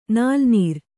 ♪ nālnīr